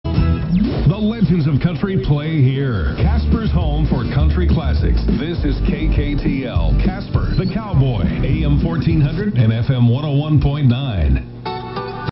(recording) - Station received on the RSP2 Pro SDR (Software Defined Radio)